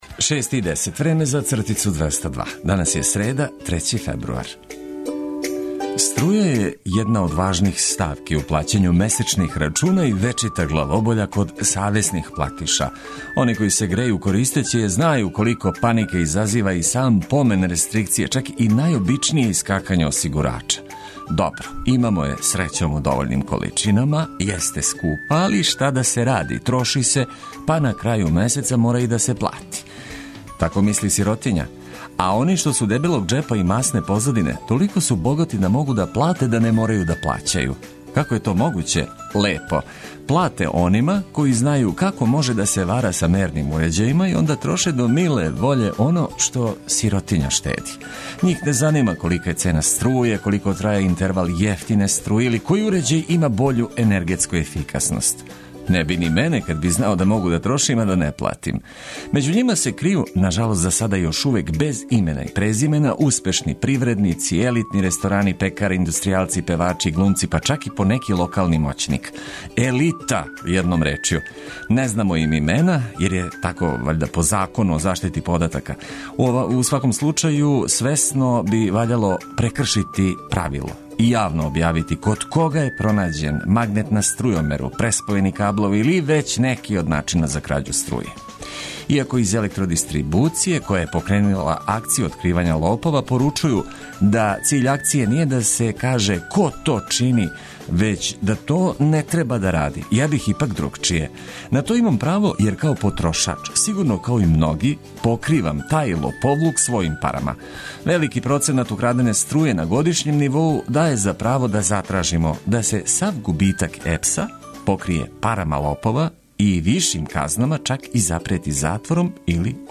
У нови дан уз важне информације, и музику за лагодније и веселије устајање на 202 начина.